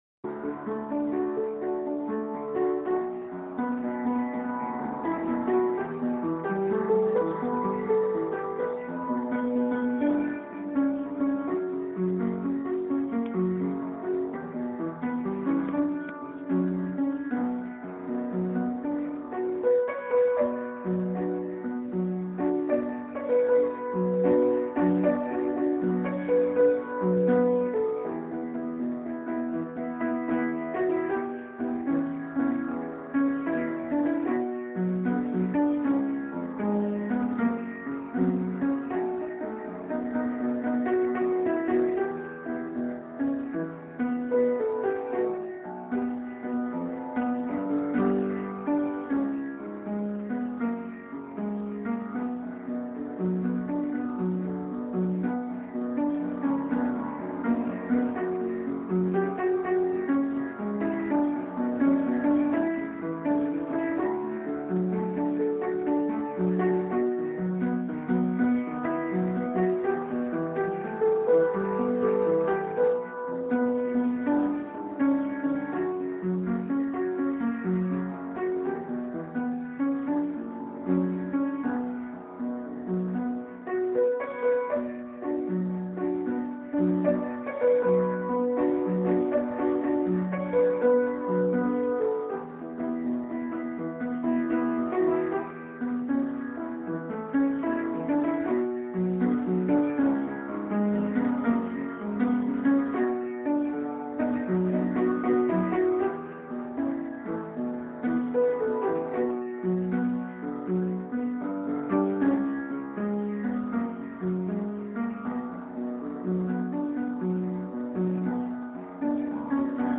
Есть такая вот минусовка